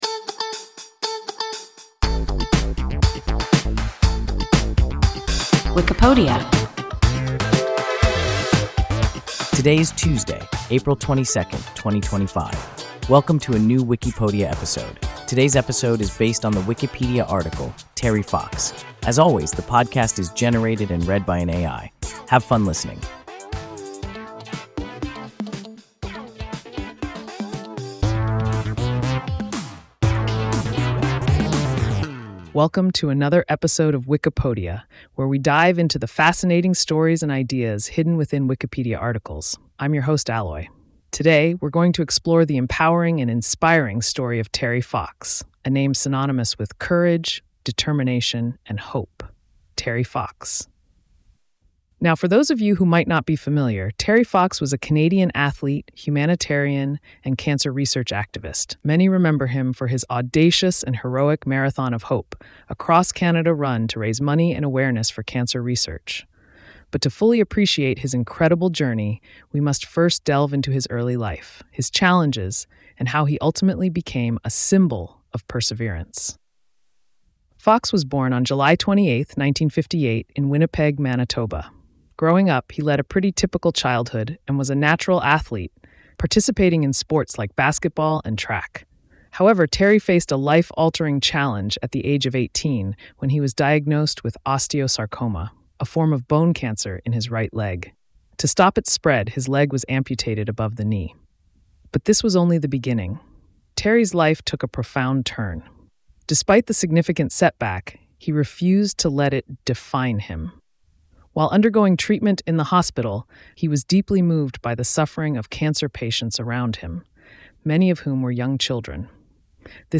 Terry Fox – WIKIPODIA – ein KI Podcast